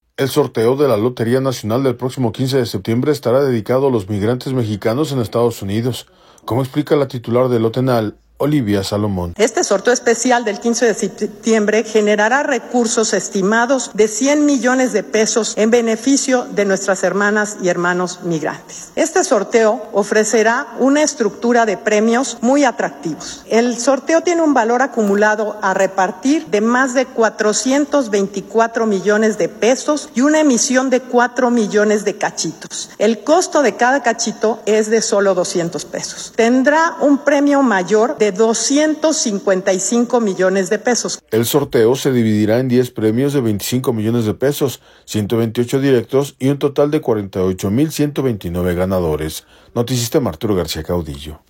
El sorteo de la Lotería Nacional del próximo 15 de septiembre estará dedicado a los migrantes mexicanos en Estados Unidos, como explica la titular de Lotenal, Olivia Salomón.